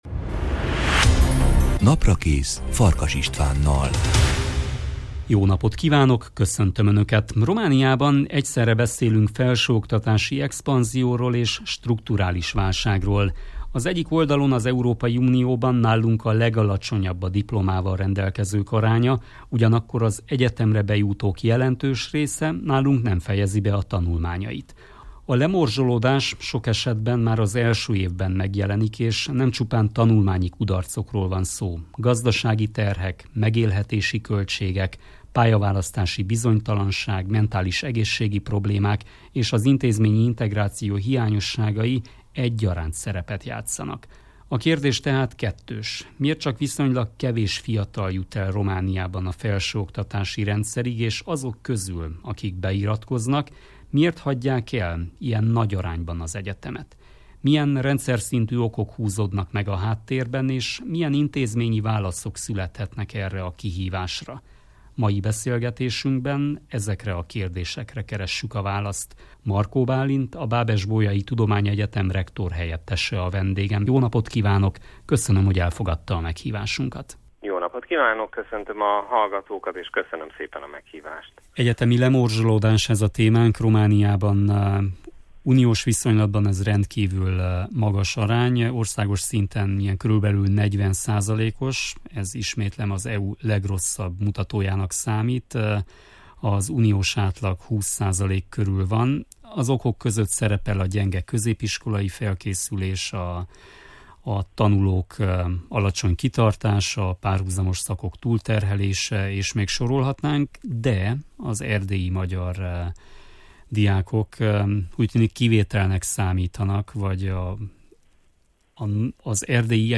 Mai beszélgetésünkben ezekre a kérdésekre keressük a választ.